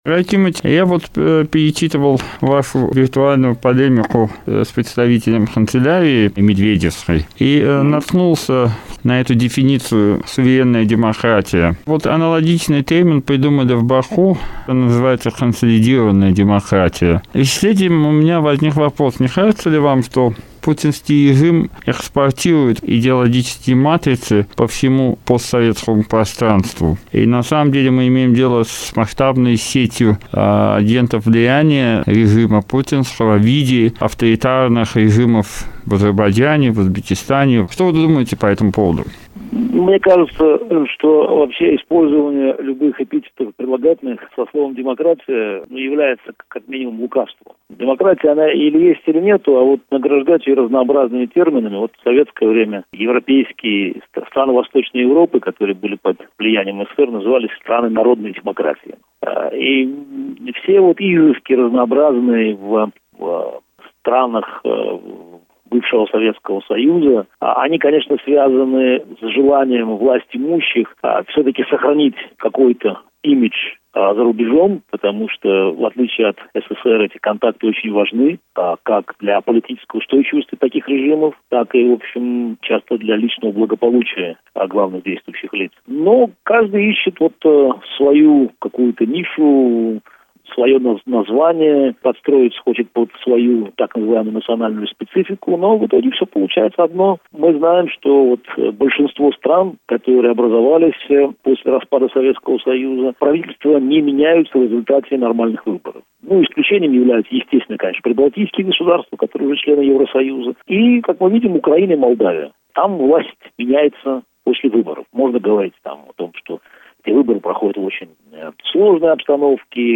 Интервью РадиоАзадлыг с политиком Гарри Каспаровым, февраль 2010 года